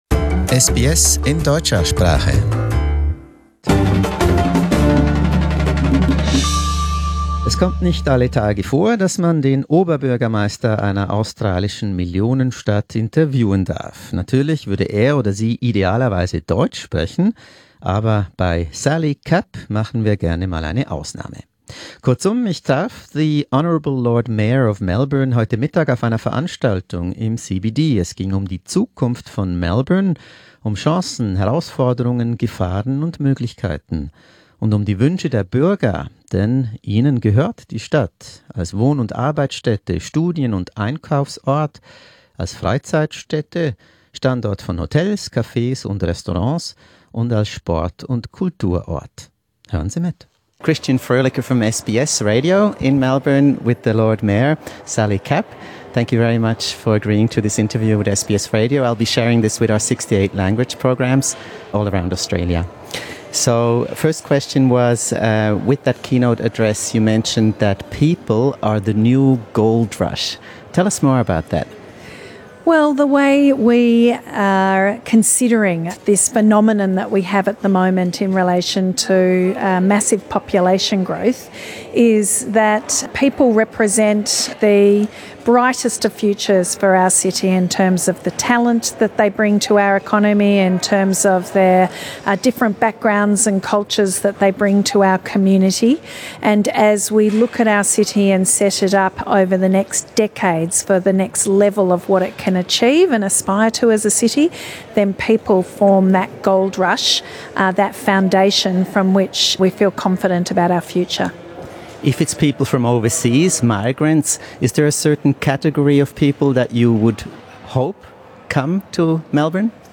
Sally Capp was the keynote speaker at a public event about Melbourne and its future as one of the world's most liveable cities. SBS German seized the opportunity to ask the Lord Mayor to share her vision for Australia's fastest growing city and how to address some key challenges lying ahead.